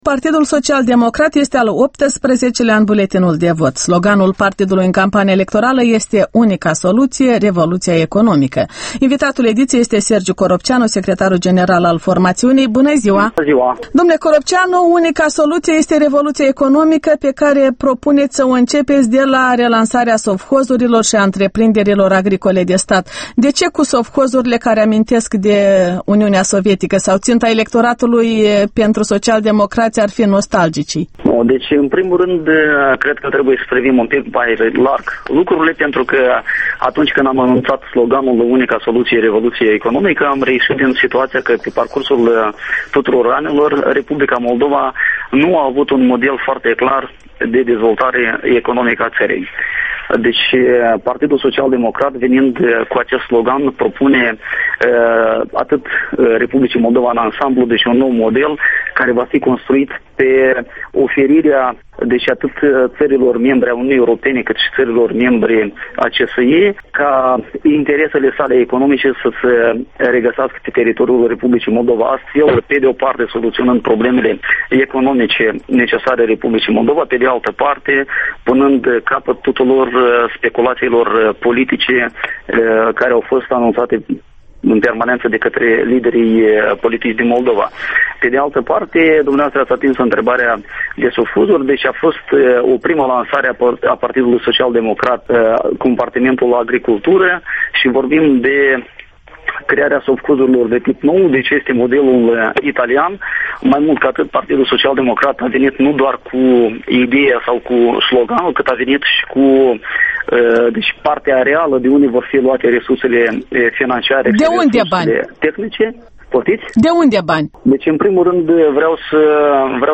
Interviu Electorala 2010